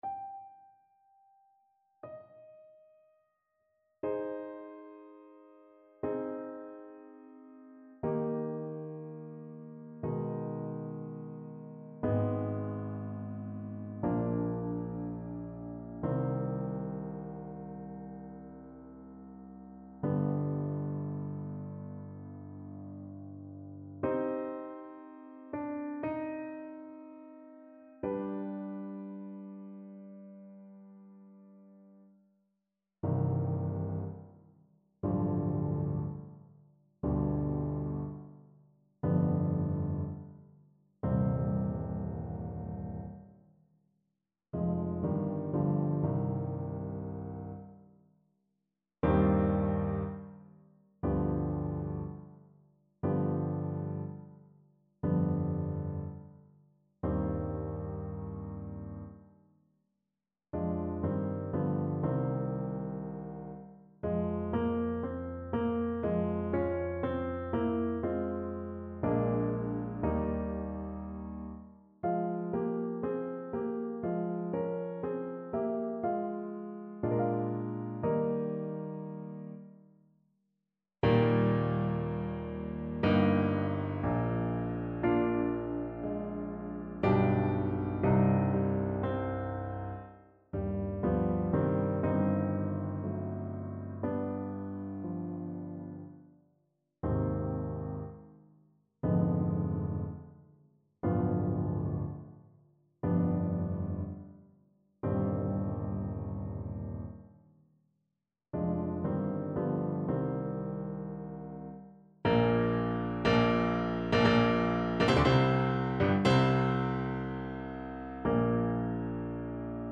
Adagio ma non troppo
4/4 (View more 4/4 Music)
Classical (View more Classical Clarinet Music)